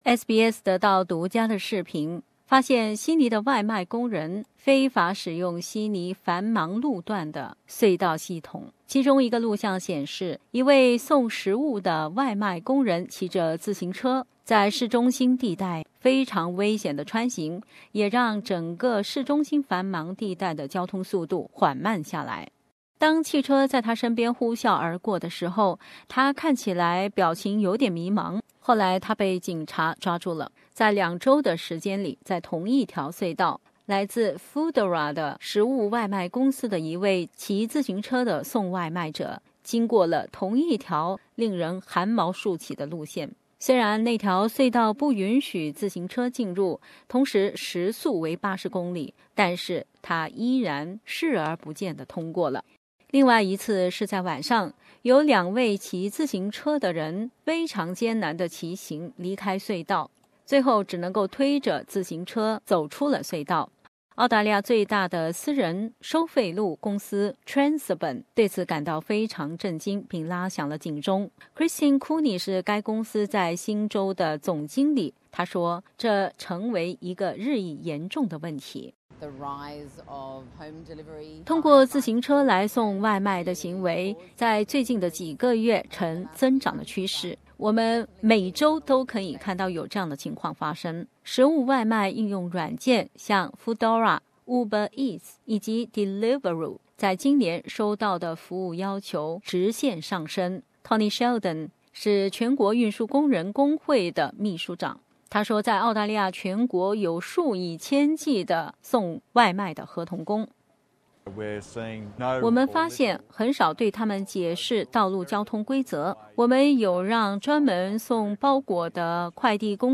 下面请听本台的独家报道。